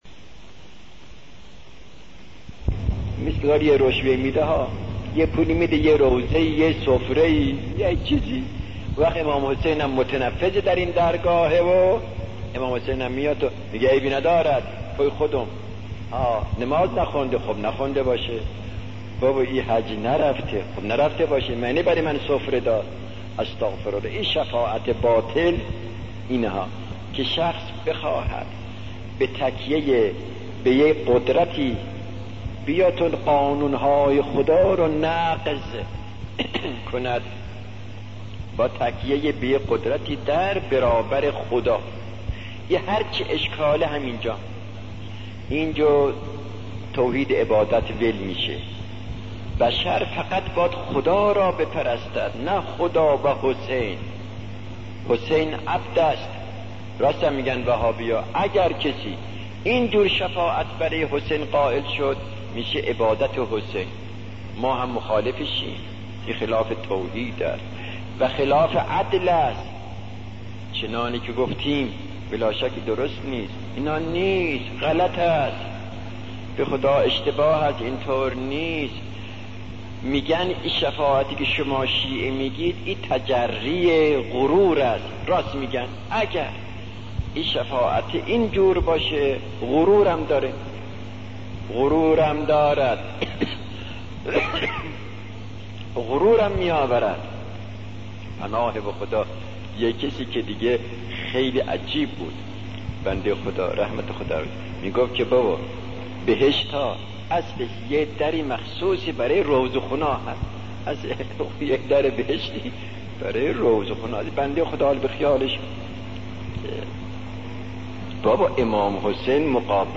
در این صوت شبهات پیرامون شفاعت توسط زبان شهید آیت الله دستغیب بررسی میگردد: با گناه کاری نمیشود دوست امام حسین (ع) بود و منتظر شفاعت ماند.